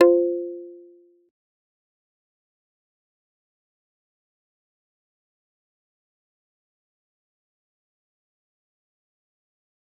G_Kalimba-F4-pp.wav